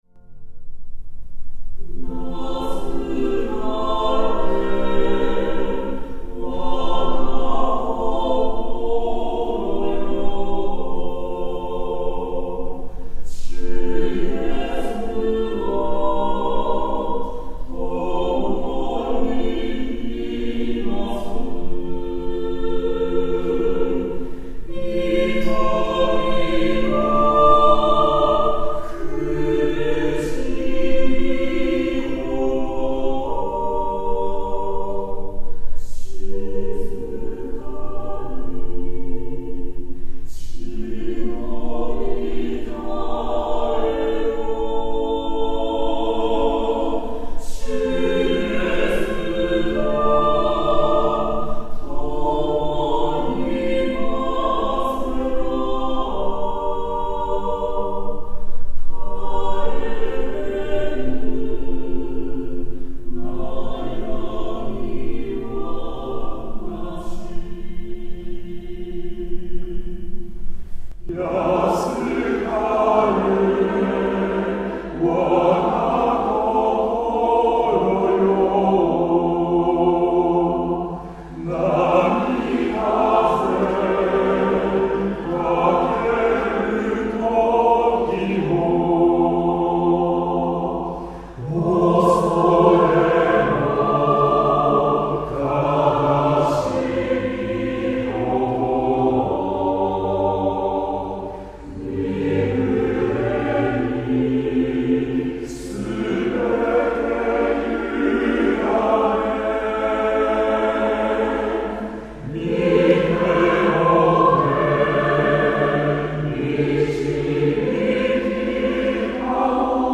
リバーブ強調(M-R)